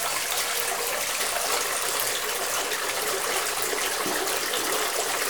bath4.wav